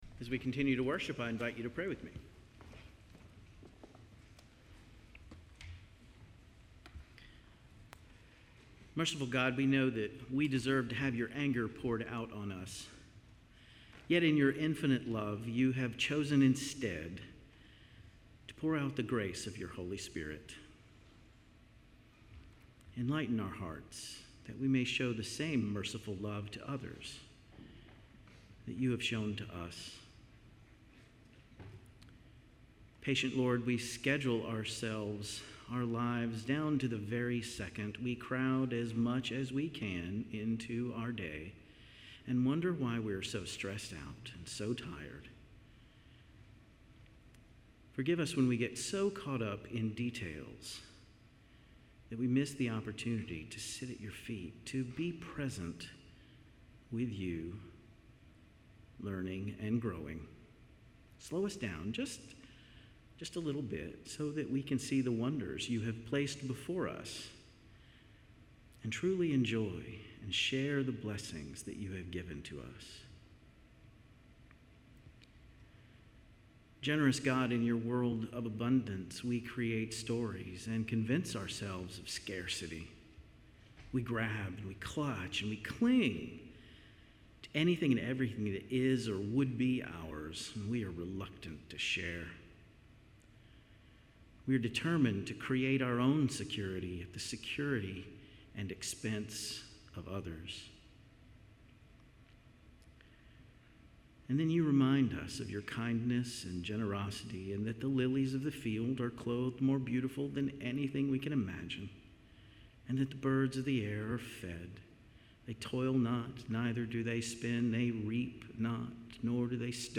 Service Type: Traditional Service